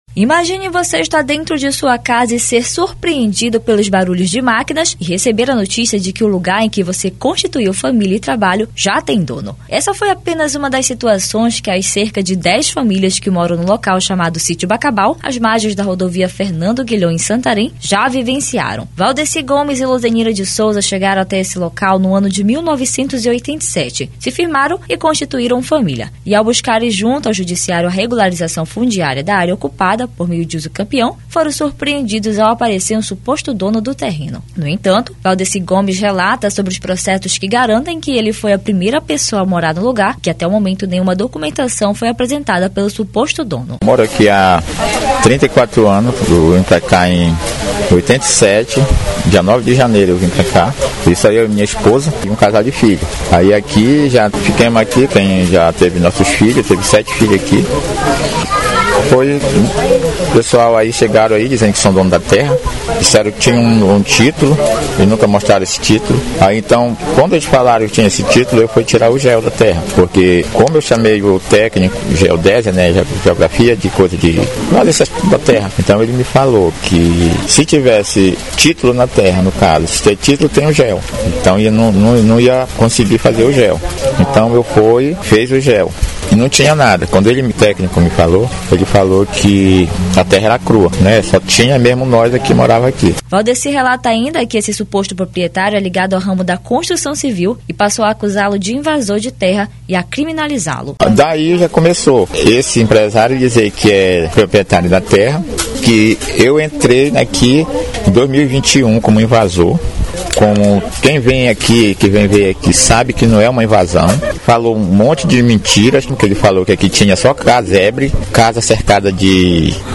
Famílias sofrem intimidação, criminalização e ameaças de despejo em sítio localizado em Santarém, no Oeste do Pará. Cerca de 10 famílias residem no local há mais de 30 anos e lutam para garantir regularização fundiária. A reportagem